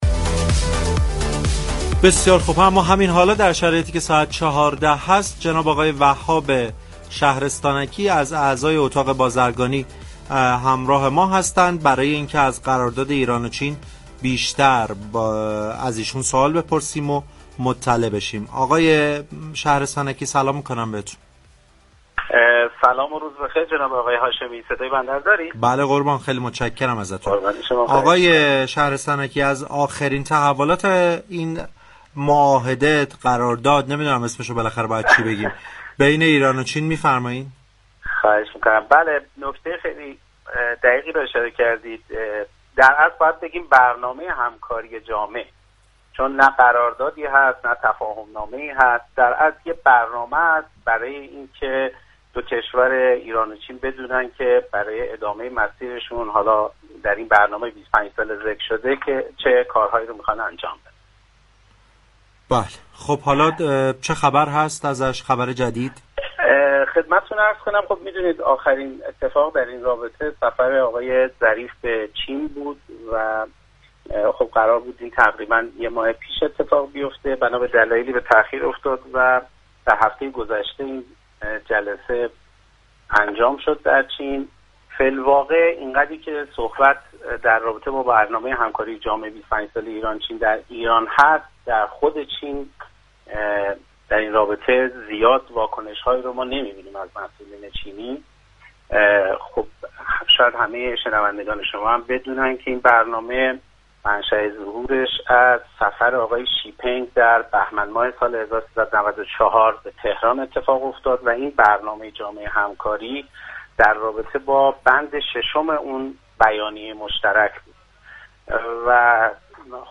درگفتگو با برنامه بازار تهران رادیو تهران